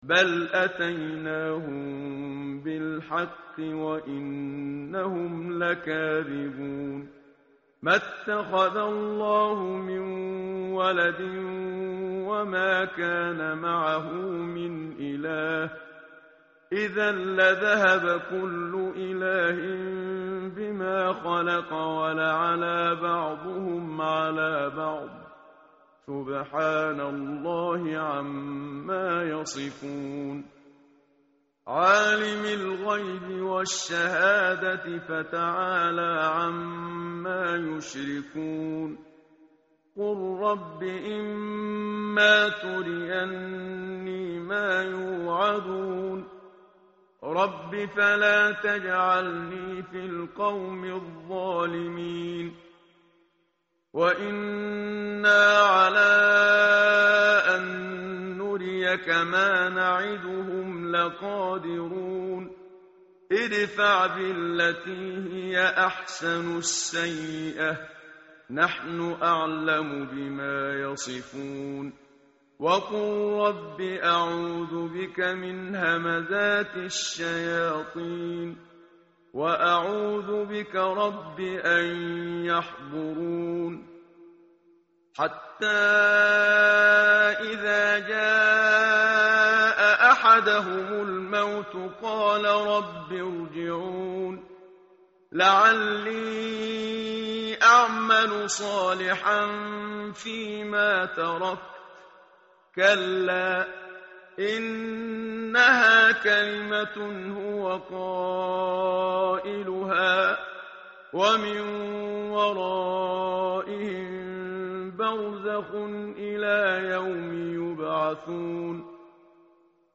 tartil_menshavi_page_348.mp3